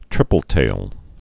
(trĭpəl-tāl)